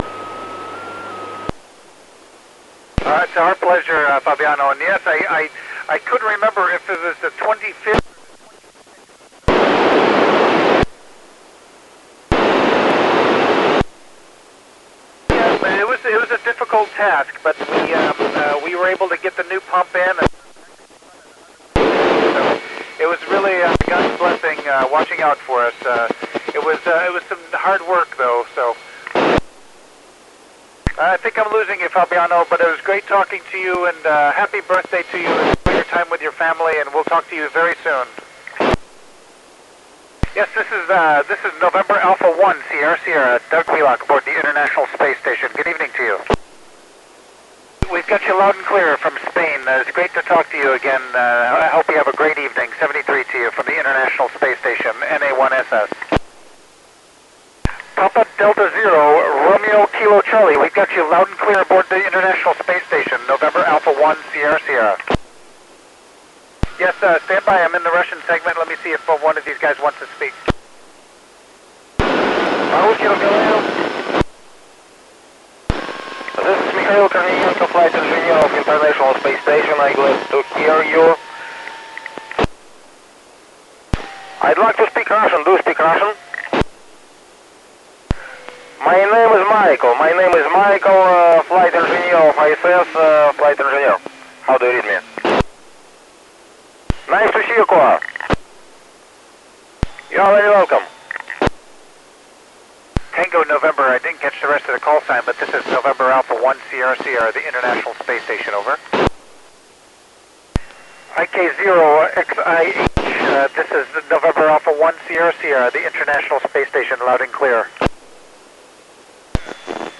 Recording of Colonel Douglas Wheelock on his pass over EU on 25 august 2010.
Mikhail Kornienko comes on the mic.